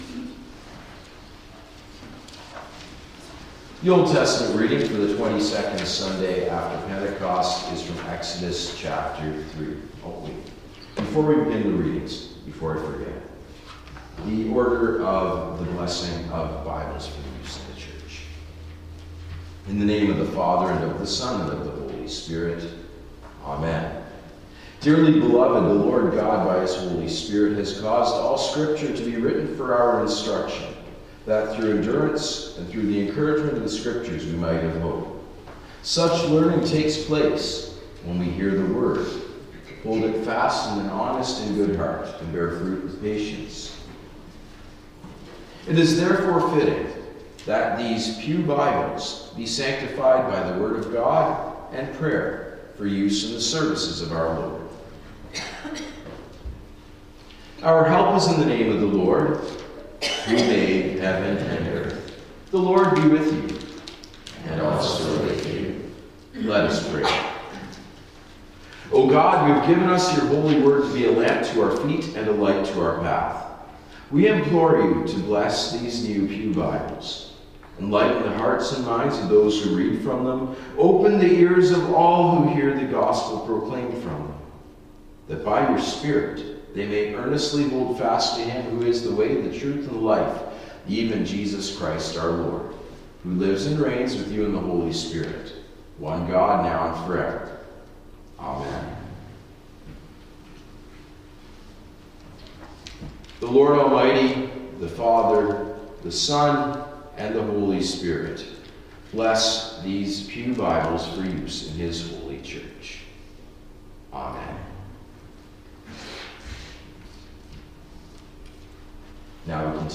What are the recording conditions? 22nd Sunday After Pentecost November 9, 2025